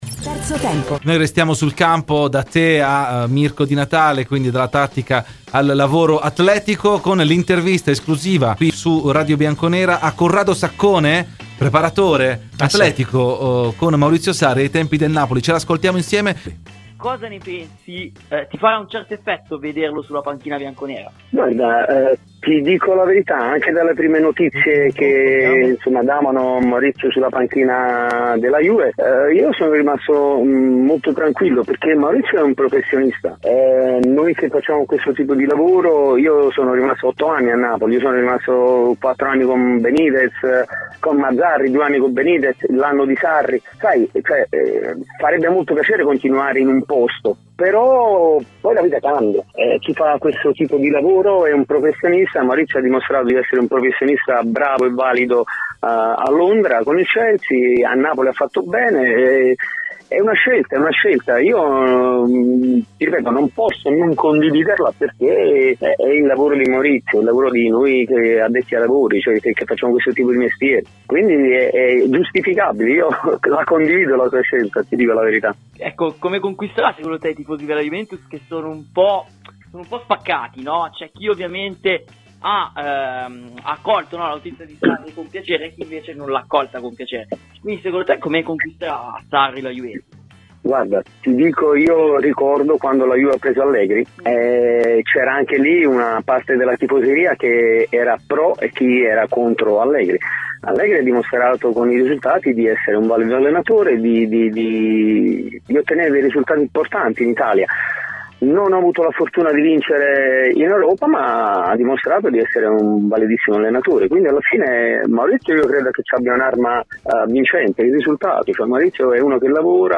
Intervista
in onda su "Terzo tempo" su Radio Bianconera.